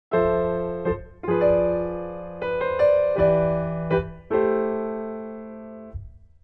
Subdominant Minor IVm6(+11)